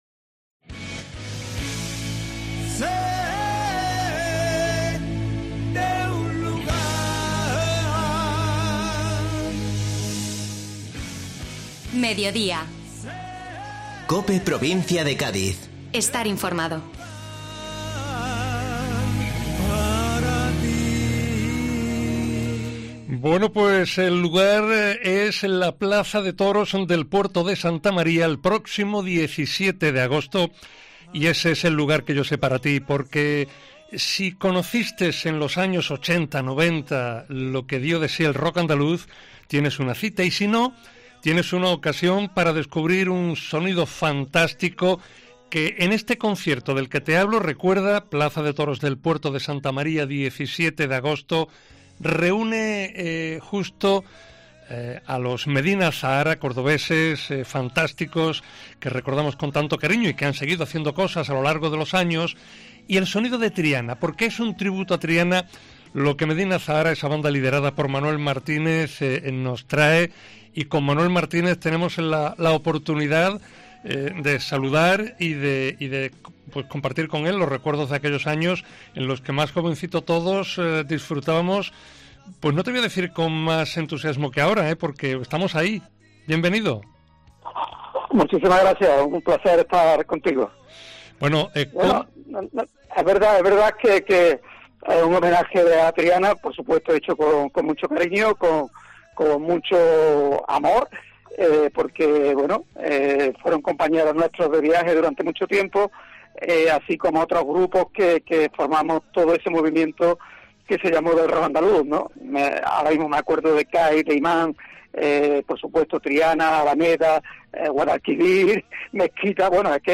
Escucha aquí al líder de Medina Azahara: "Fueron compañeros nuestros de viaje durante mucho tiempo junto a otros grupos como Cai, Imán, por supuesto Triana, Alameda, Guadalquivir, Mezquita..."